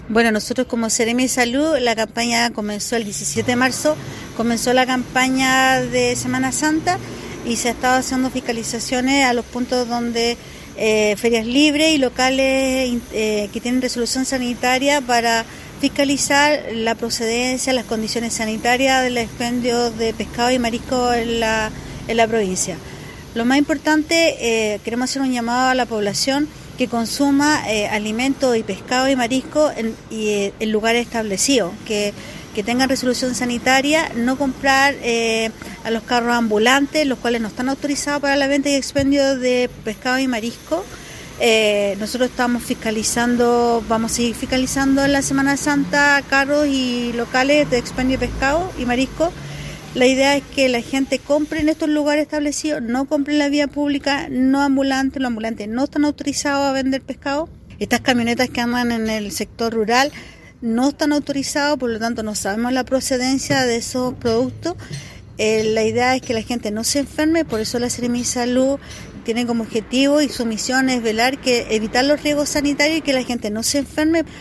se efectuó un punto de prensa en el frontis de Delegación Presidencial Provincial de Melipilla